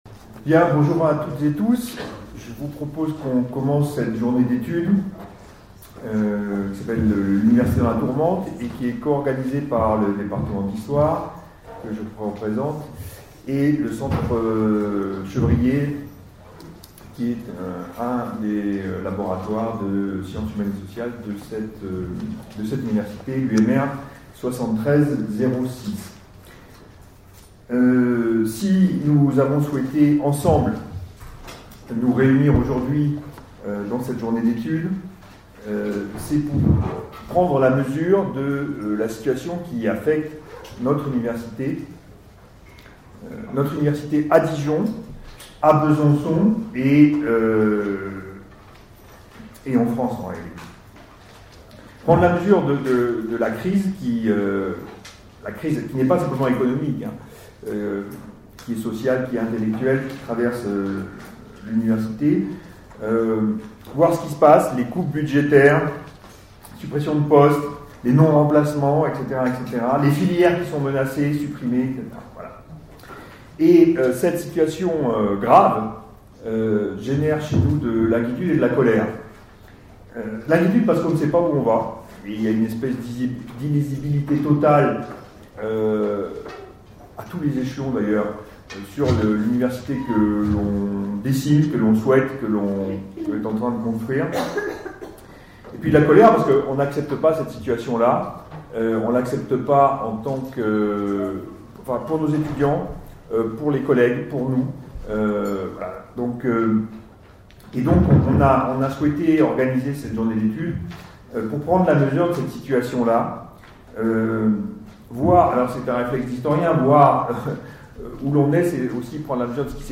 Les universités dans la tourmente - Table ronde | Canal U